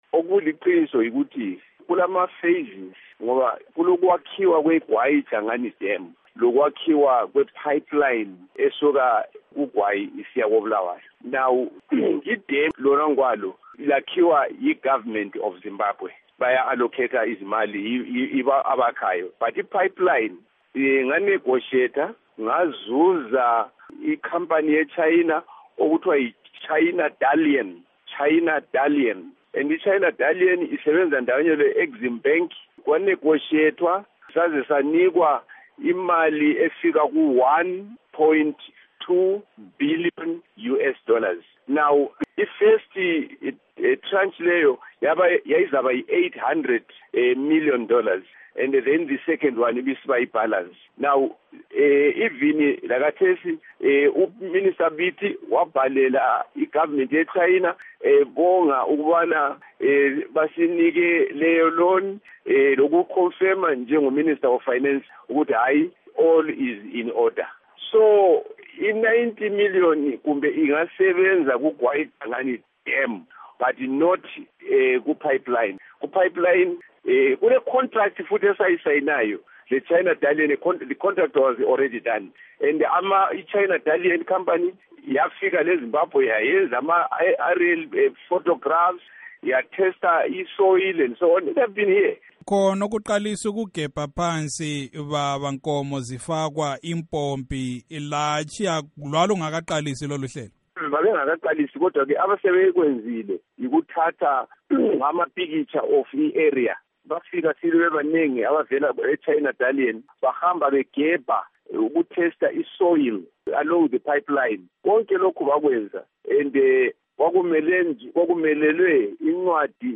Ingxoxo loMnu. Samuel Siphepha Nkomo